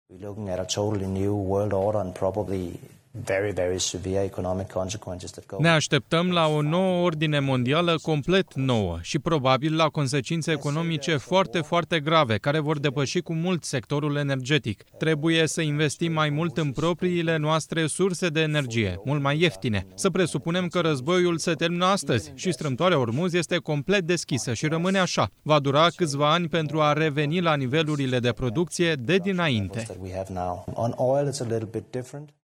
O spune comisarul european pentru Energie și Locuințe, Dan Jørgensen: oficialul a declarat, la o conferință în Madrid, că urmează o vară dificilă pentru economia internațională, chiar dacă strâmtoarea Ormuz s-ar deschide cât mai curând.
22apr-13-Jorgensen-TRADUS-despre-energie.mp3